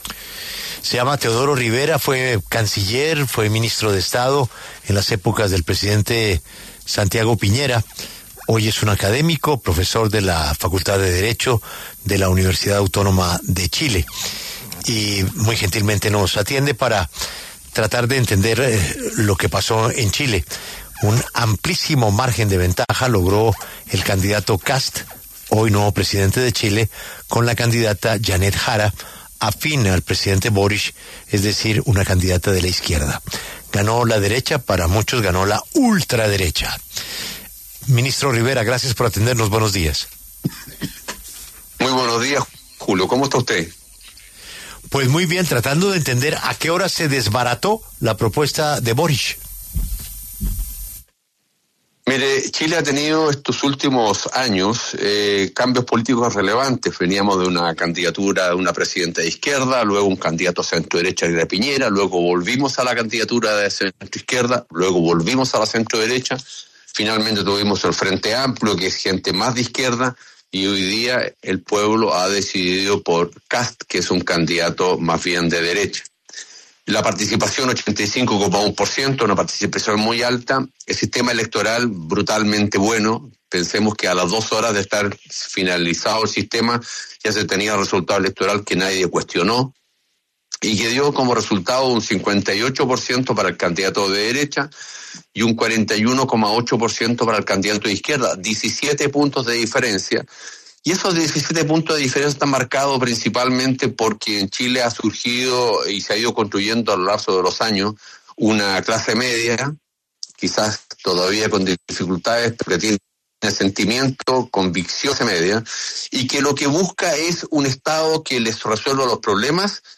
En diálogo con La W, Teodoro Ribera, exministro de Relaciones Exteriores y exministro de Estado del gobierno de Sebastián Piñera, analizó la amplia victoria del candidato de derechas de Chile.